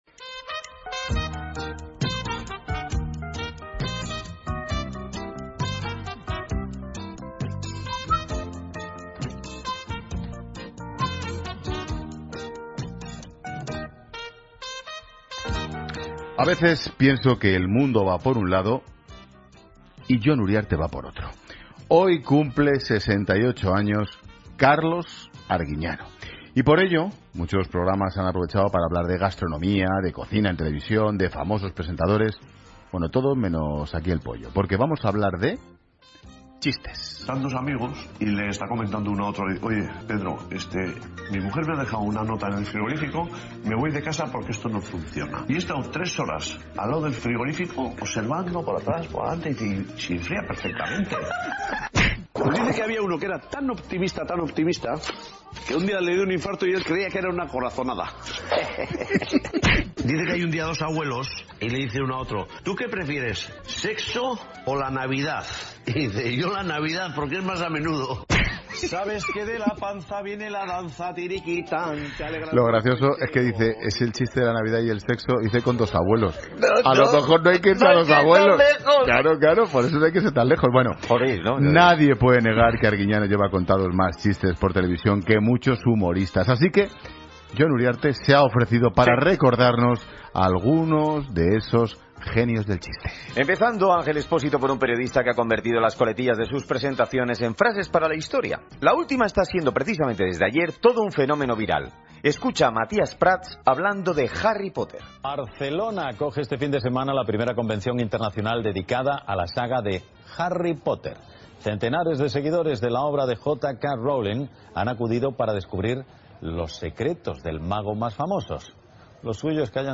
Ronda de chistes en 'La Tarde'